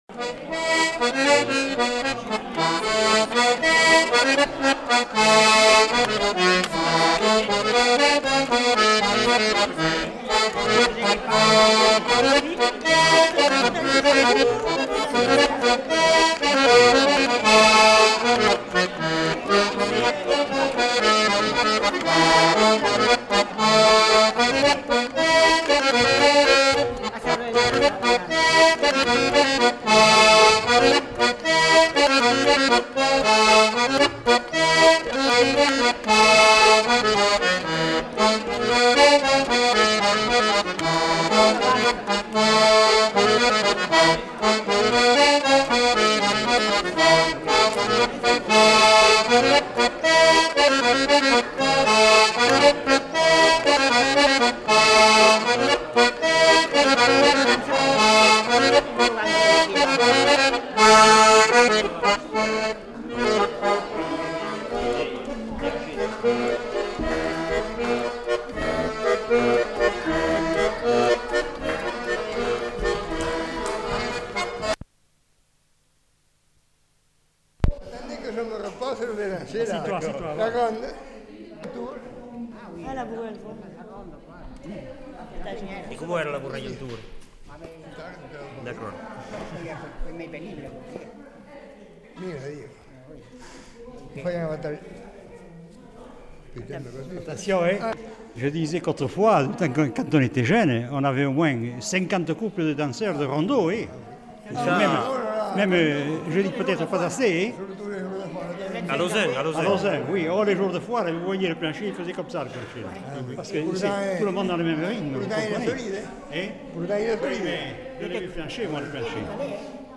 Aire culturelle : Haut-Agenais
Lieu : Lauzun
Genre : morceau instrumental
Instrument de musique : accordéon diatonique
Danse : bourrée